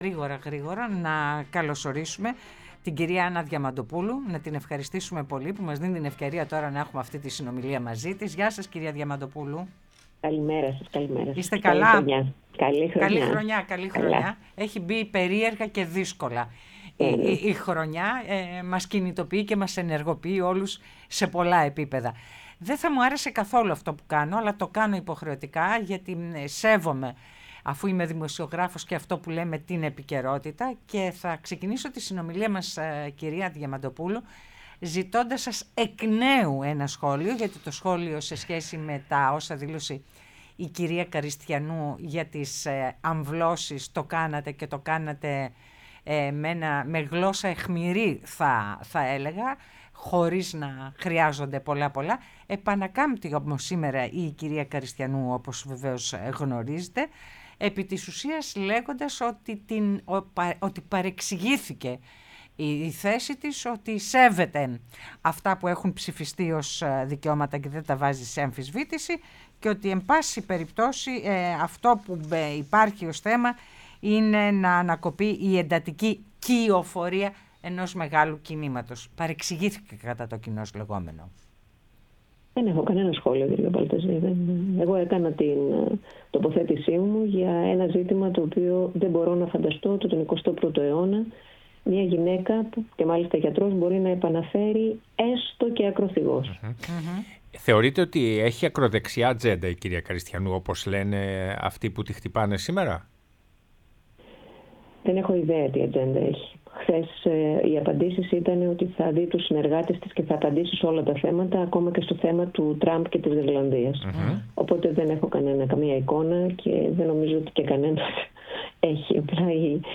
Άννα Διαμαντοπούλου, Υπεύθυνη Πολιτικού Σχεδιασμού ΠΑΣΟΚ, πρώην υπουργός, μίλησε στην εκπομπή «Ναι, μεν Αλλά»